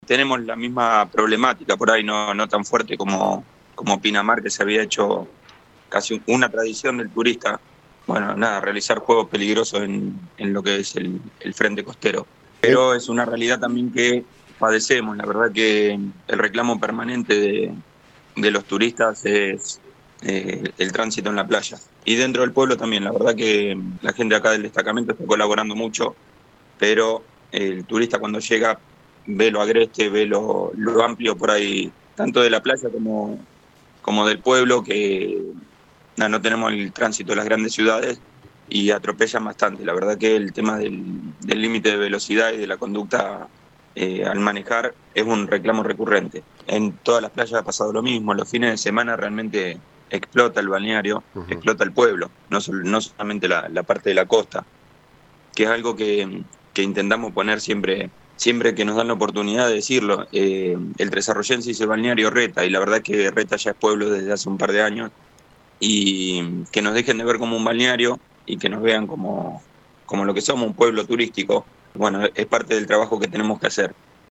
En el marco de una temporada estival que alterna picos de masividad con un consumo austero, el delegado de Reta, Diego Zyncenko, analizó en dialogo con LU24 la actualidad del destino turístico.
Uno de los puntos más críticos de la entrevista fue la seguridad en el frente costero.